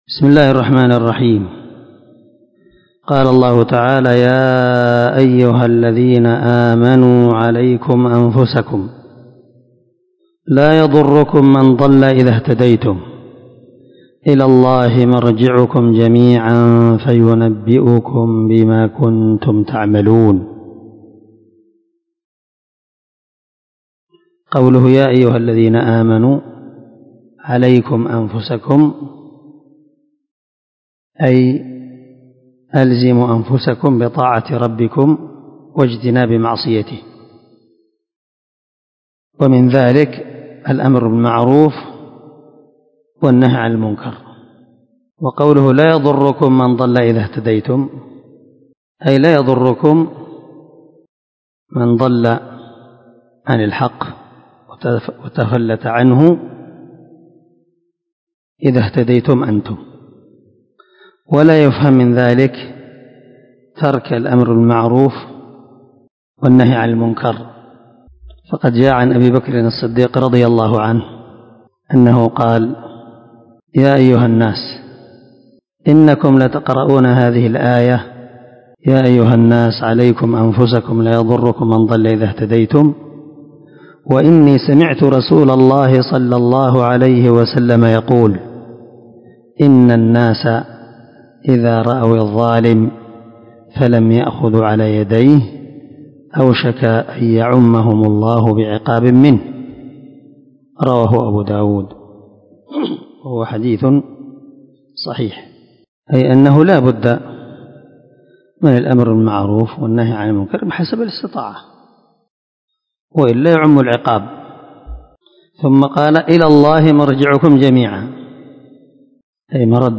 389الدرس55تفسير آية ( 105 - 109 ) من سورة المائدة من تفسير القران الكريم مع قراءة لتفسير السعدي